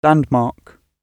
landmark-gb.mp3